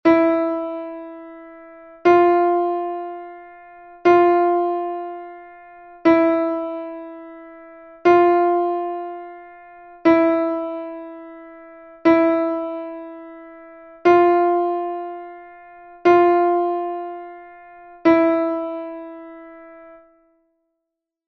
Exercise 4: E-F diatonic semitone exercise.
ejercicio_semitono_diatonico_mi-fa.mp3